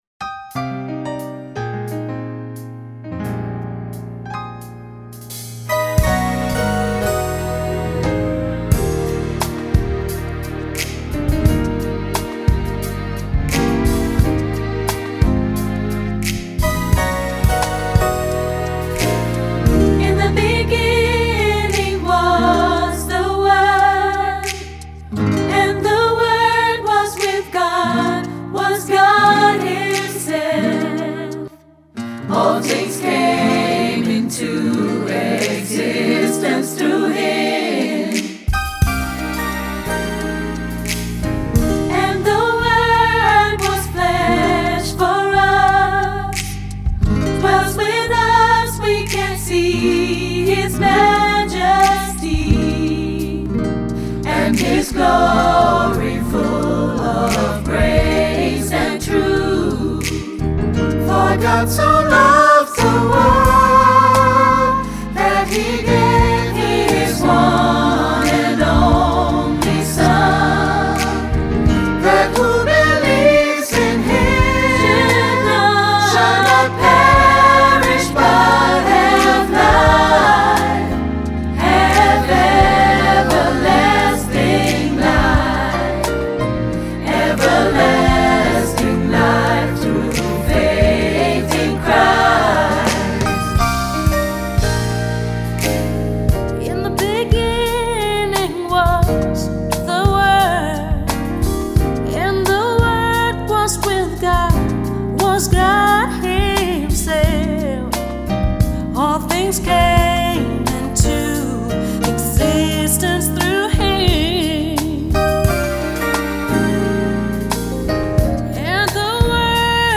GOSPELNOTEN
• SAB, auch SSA + Piano